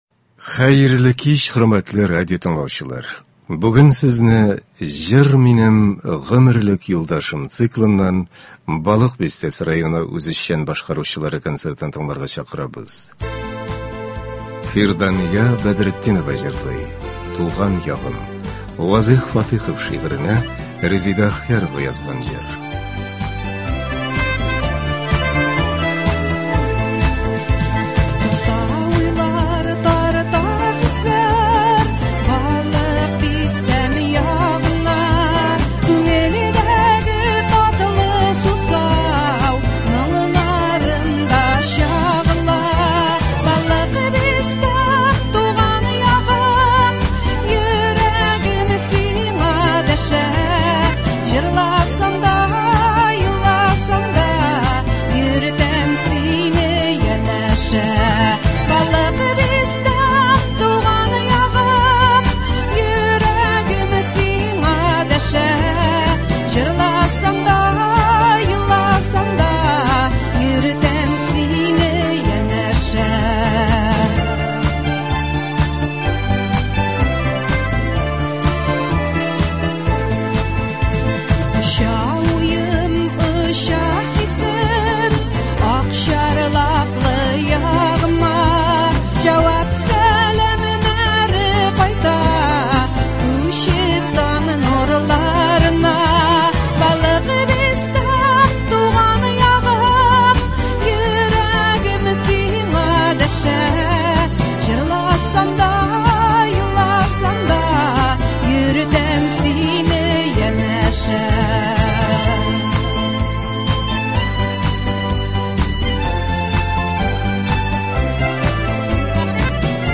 Бәйрәм концерты.